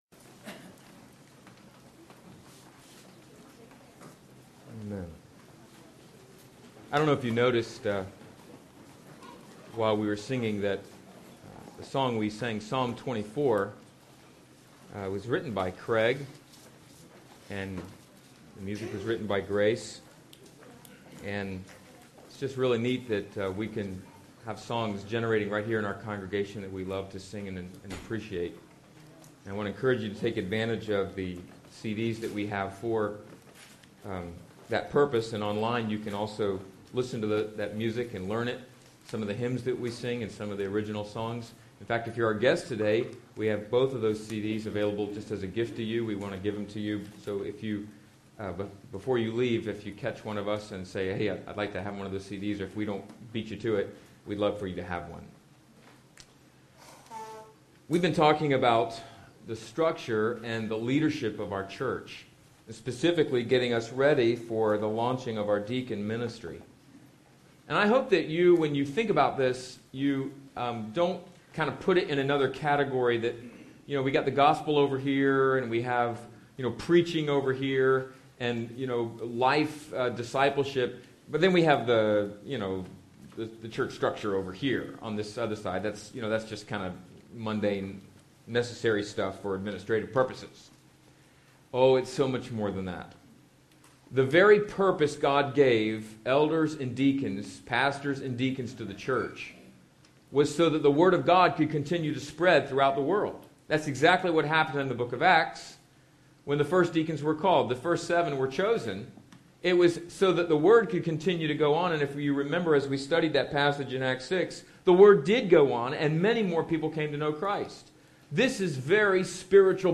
The-Difference-Between-Deacons-and-Elders-Full-sermon.mp3